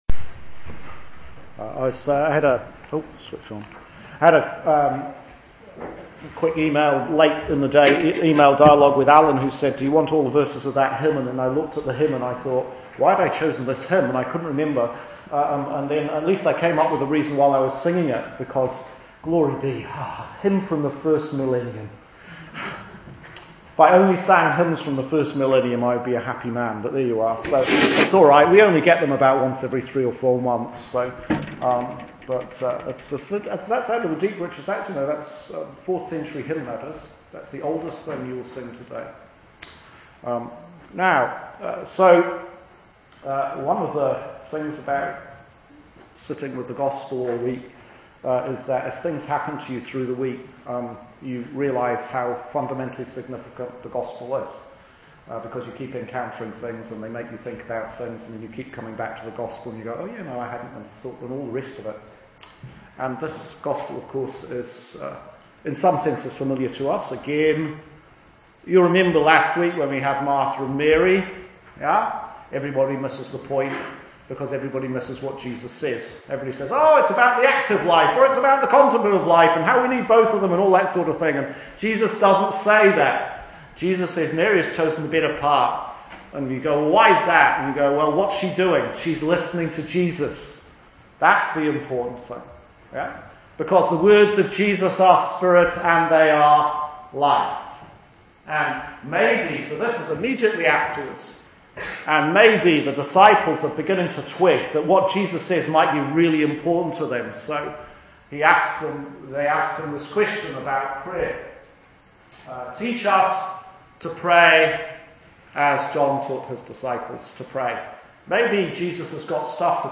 Sermon for Trinity 6, Year C 2019 Colossians 2:6-15 Luke 11:1-13